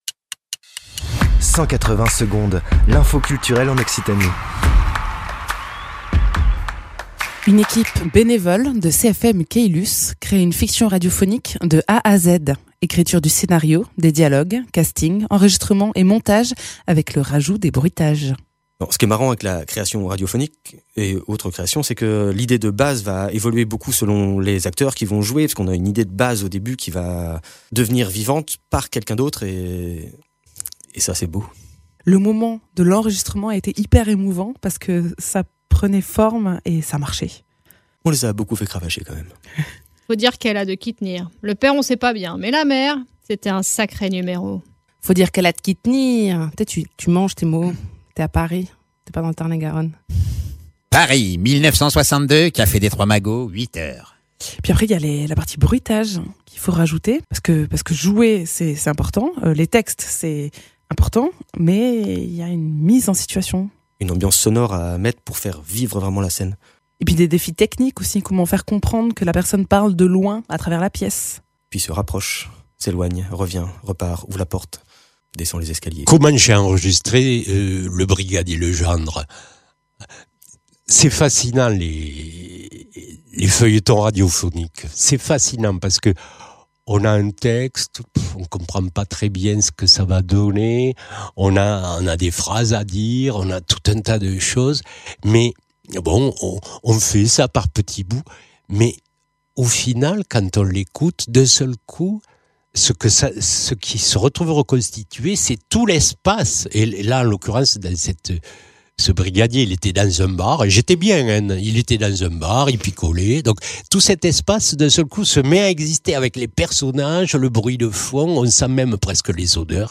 Fiction radio